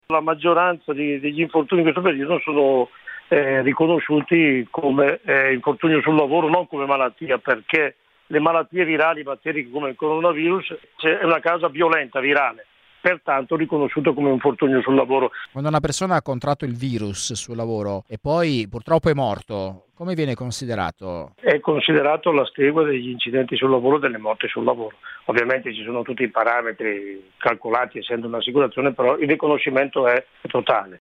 Questa mattina il presidente dell’Inail Franco Bettoni ha dichiarato ai nostri microfoni che contrarre il virus sul luogo di lavoro corrisponde ad un infortunio e non a una malattia.
intervistato